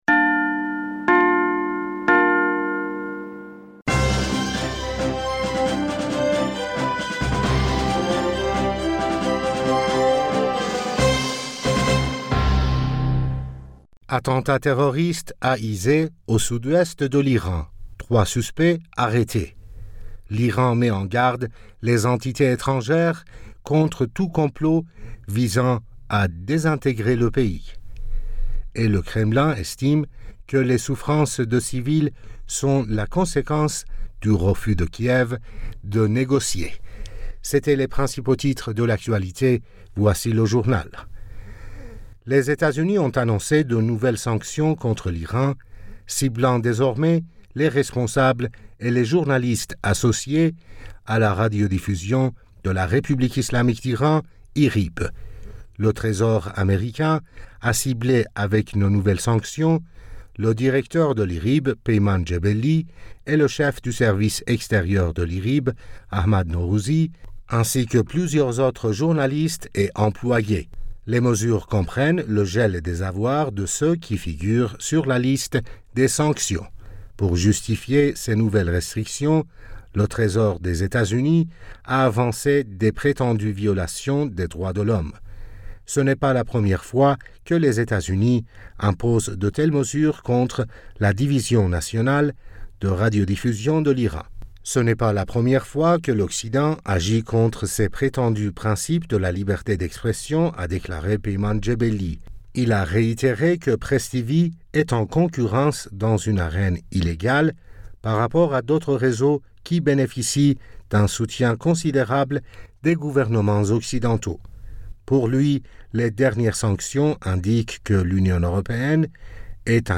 Bulletin d'information du 17 Novembre